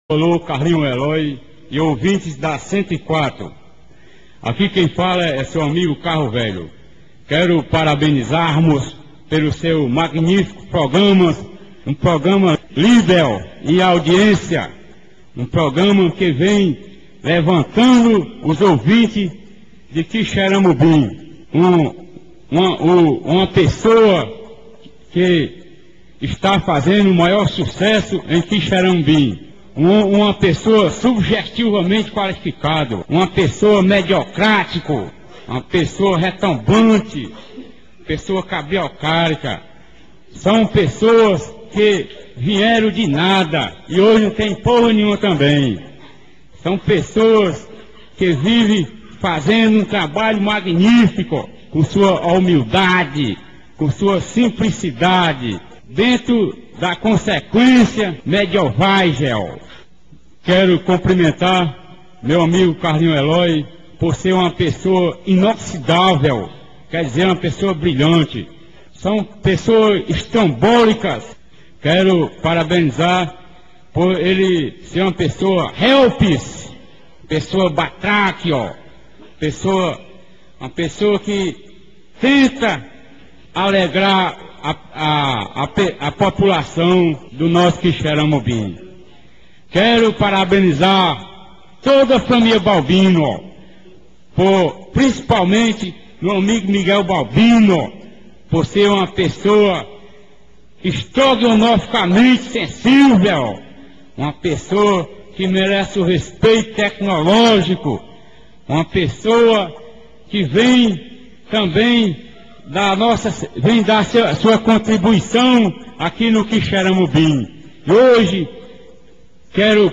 O áudio viralizou rapidamente, se tornando um dos memes mais icônicos do rádio brasileiro.